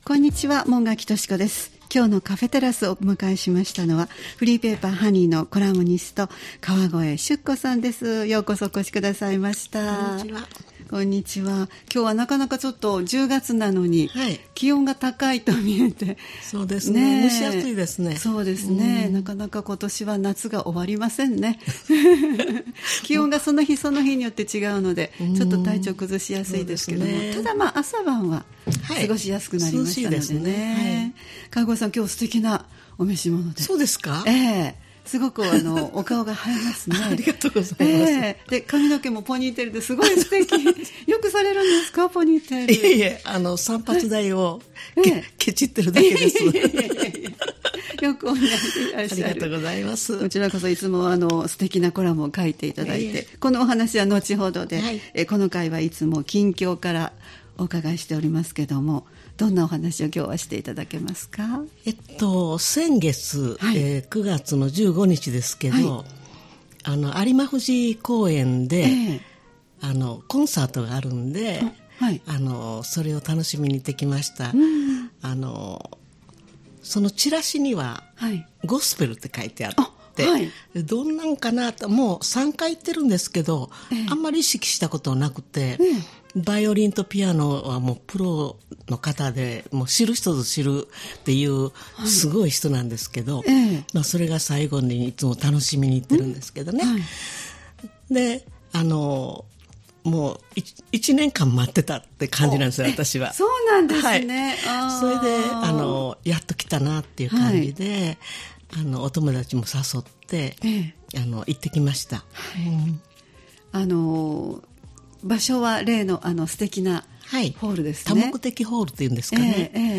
様々なジャンルで活動・活躍されている方をお迎えしてお話をお聞きするポッドキャスト番組「カフェテラス」（再生ボタン▶を押すと放送が始まります）